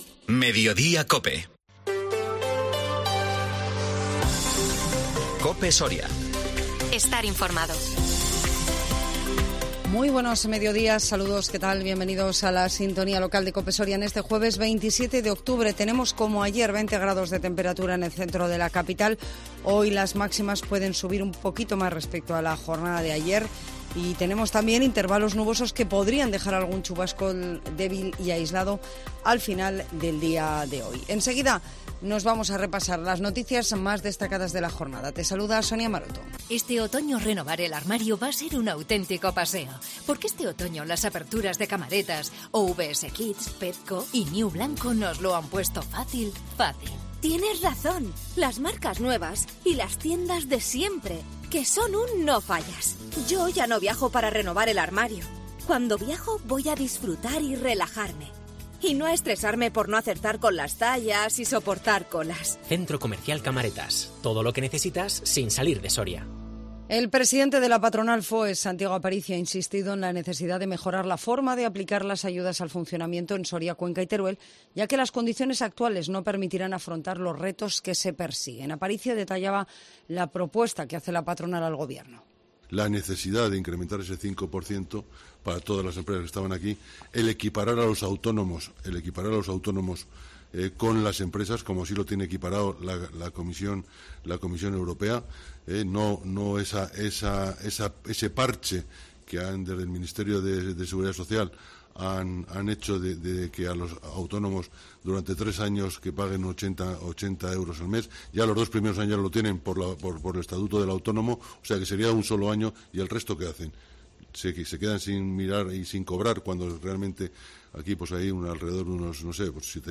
INFORMATIVO MEDIODÍA COPE SORIA 27 OCTUBRE 2022